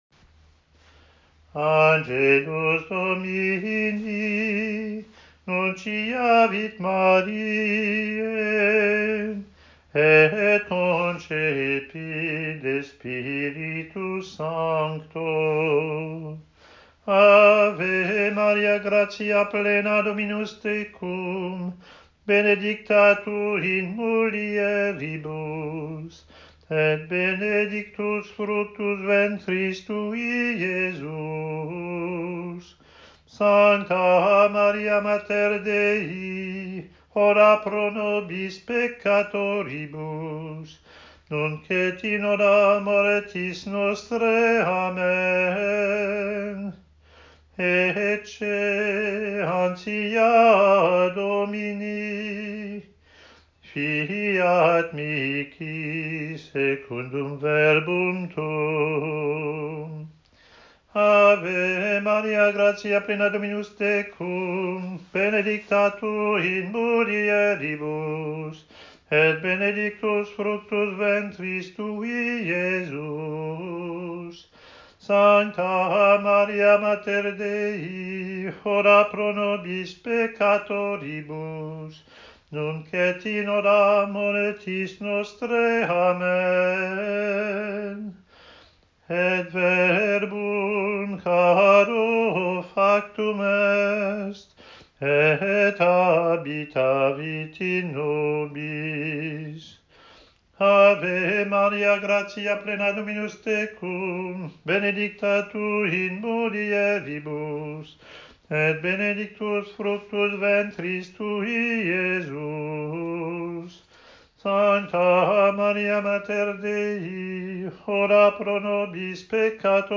Daily devotional practice of chant: